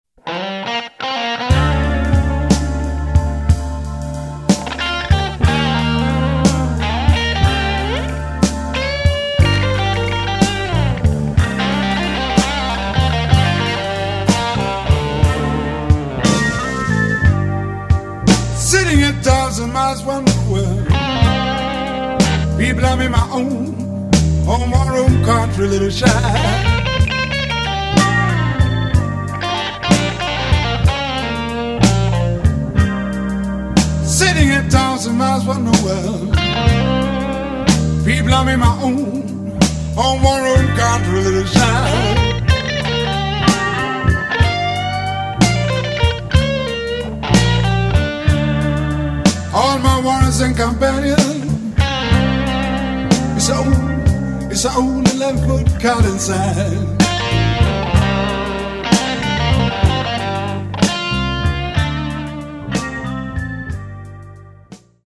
STUDIO 1983